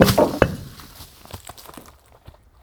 Hid_wood4.ogg